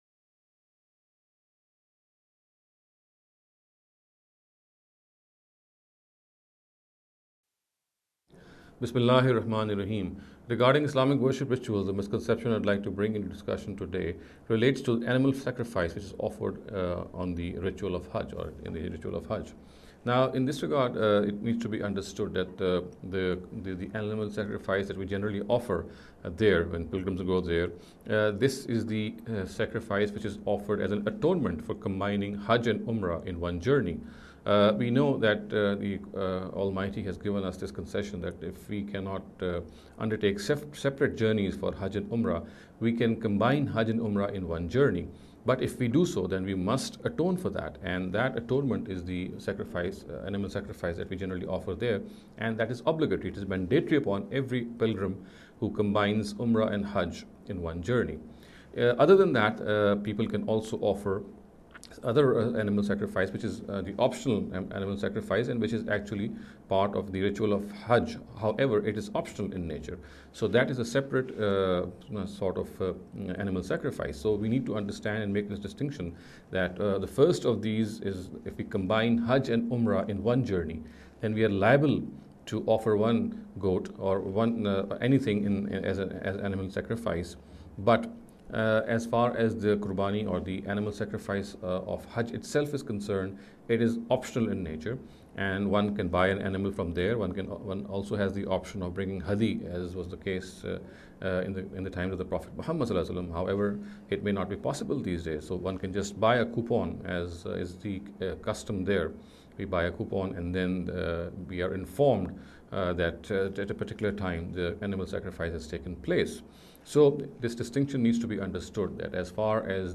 This lecture series will deal with some misconception regarding the Islamic Worship Ritual. In every lecture he will be dealing with a question in a short and very concise manner. This sitting is an attempt to deal with the question 'Animal Sacrifice on Hajj’.